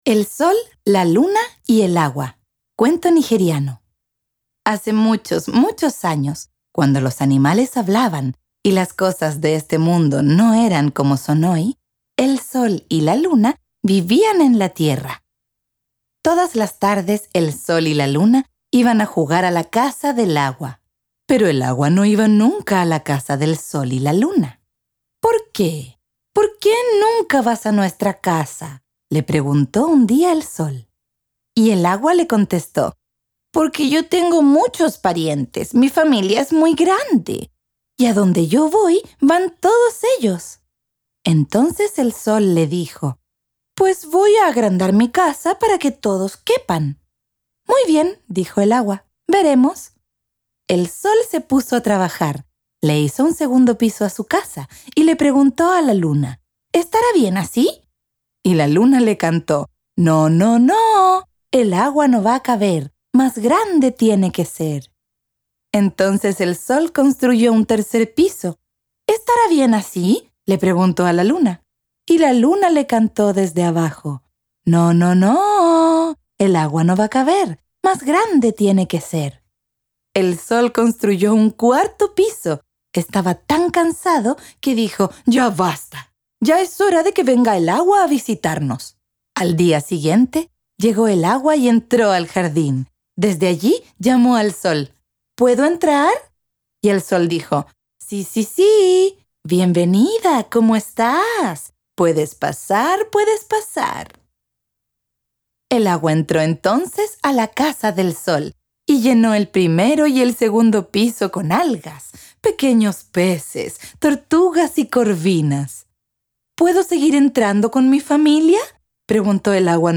Audiolibro - Extracto Tomo 2